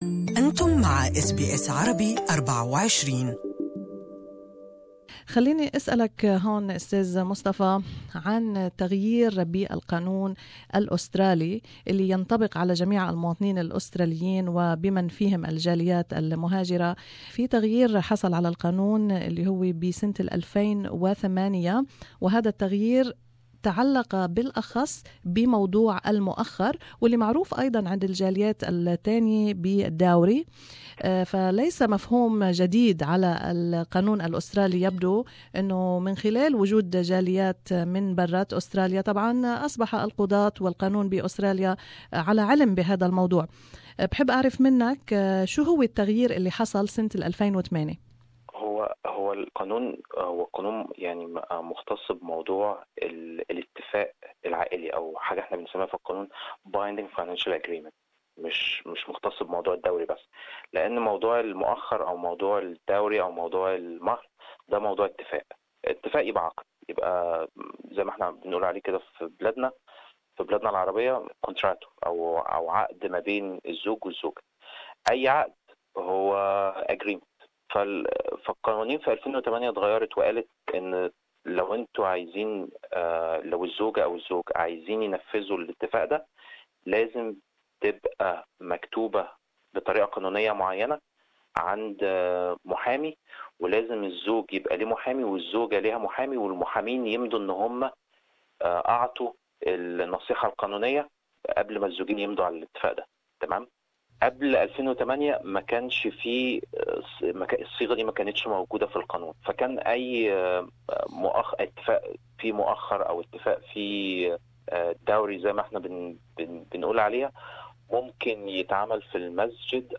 في هذا اللقاء